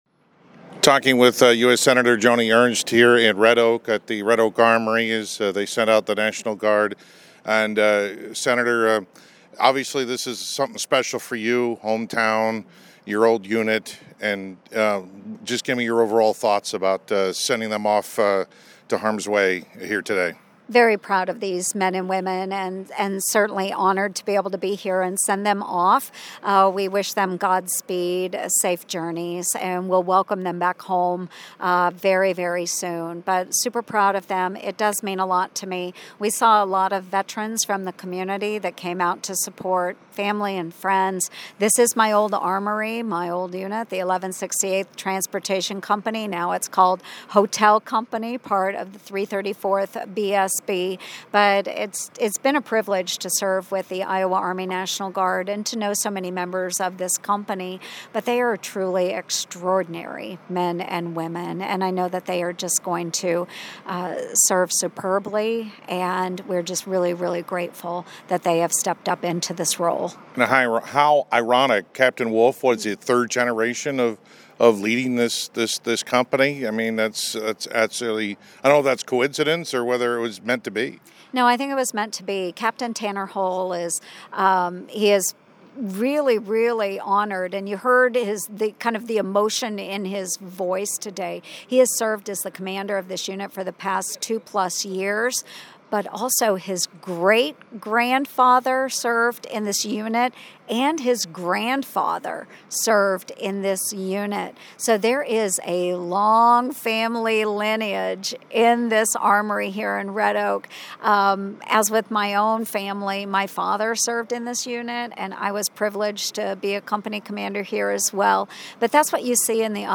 Complete Interview with Senator Joni Ernst